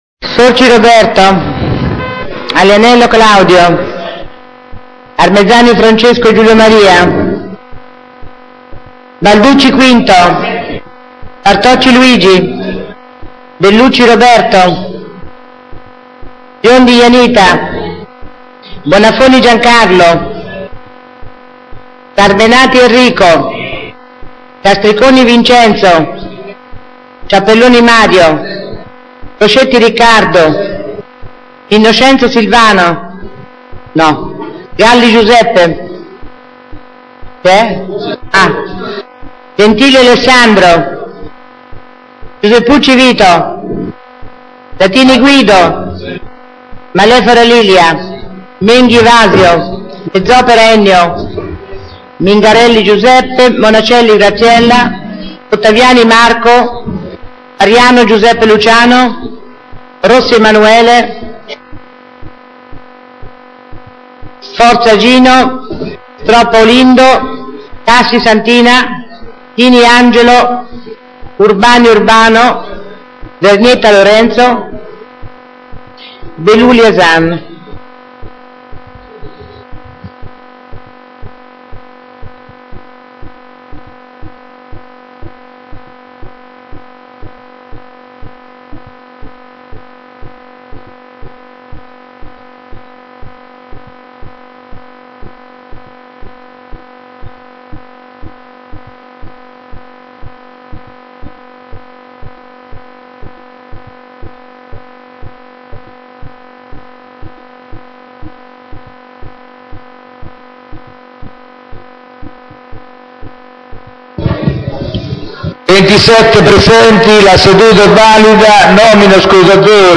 Seduta di giovedì 19 gennaio
il Consiglio Comunale è convocato presso Palazzo Chiavelli - sala consiliare giovedì 19 gennaio alle ore 16.30